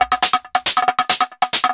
Index of /90_sSampleCDs/Best Service ProSamples vol.45 - Techno ID [AIFF, EXS24, HALion, WAV] 1CD/PS-45 AIFF Techno ID/PS-45 AIF loops/AIF drum-loops/AIF main-version